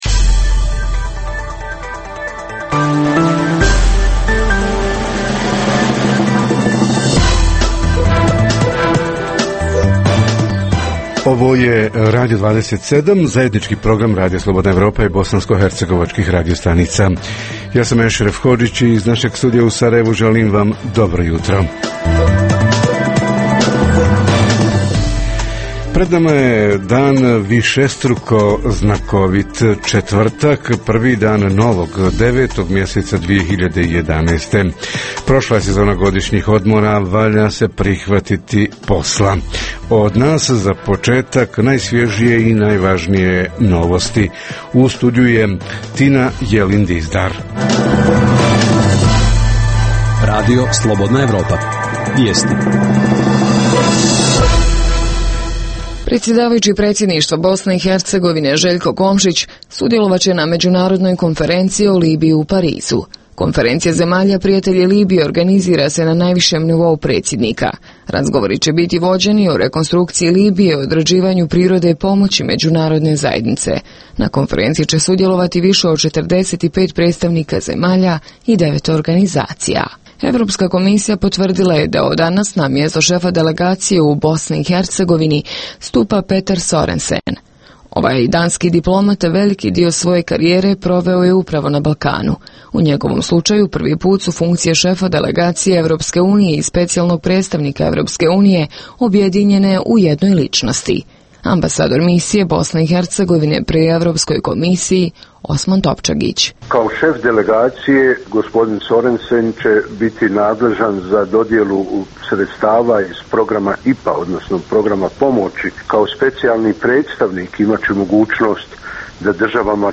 Tema jutarnjeg programa: humanitarci na djelu – ko i kako pomaže onima koji trebaju pomoć? Reporteri iz cijele BiH javljaju o najaktuelnijim događajima u njihovim sredinama.
Redovni sadržaji jutarnjeg programa za BiH su i vijesti i muzika.